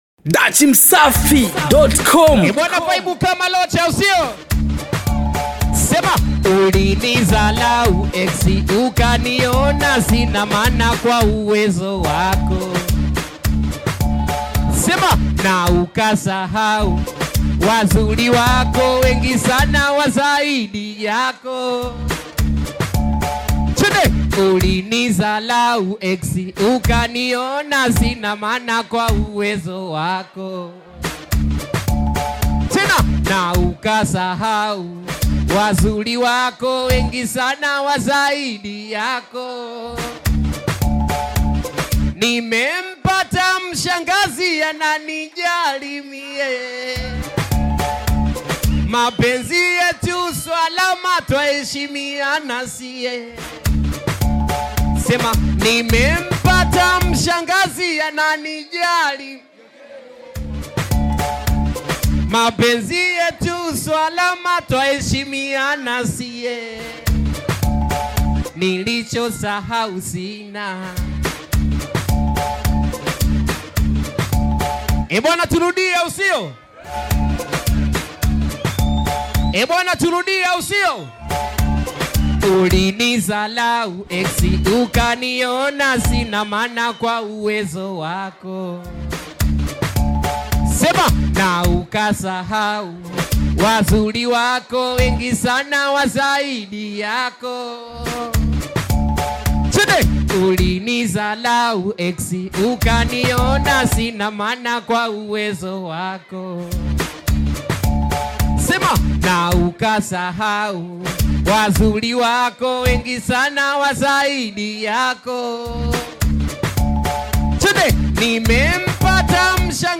Dj Mix / Beat